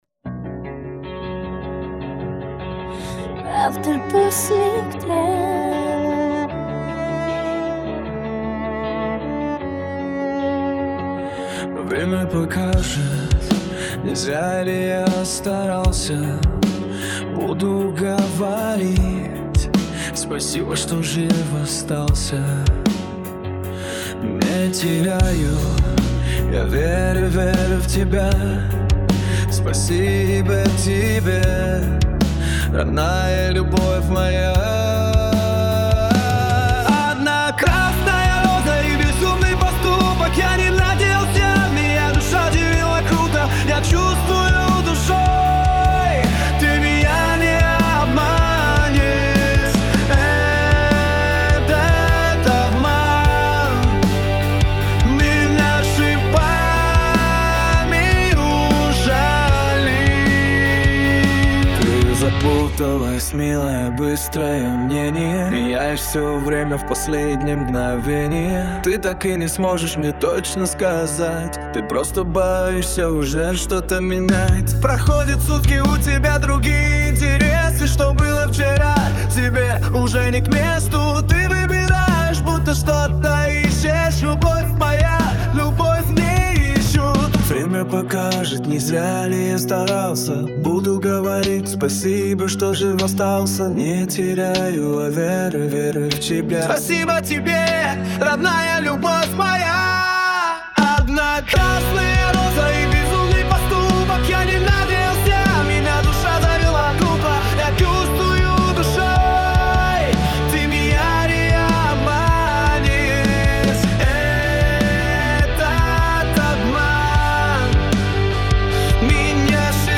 (Пример музыки и голоса создан с помощью AI)